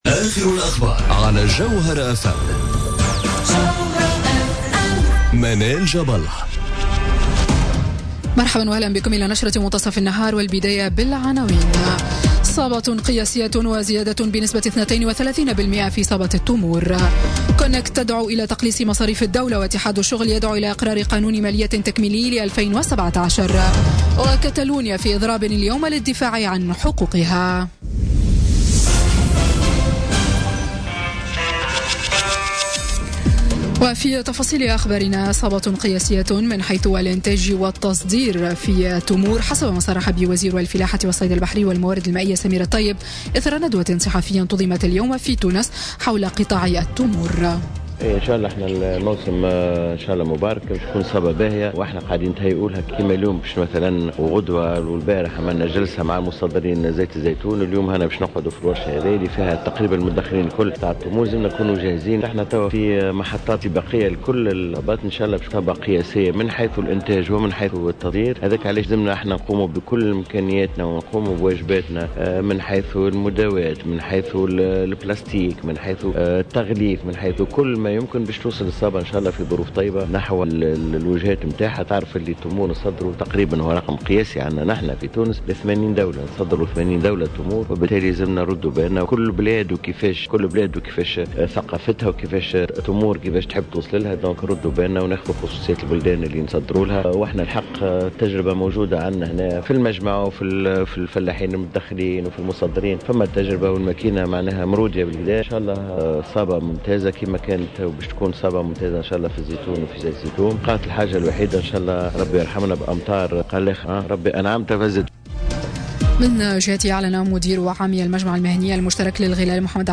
نشرة أخبار منتصف النهار ليوم الثلاثاء 3 أكتوبر 2017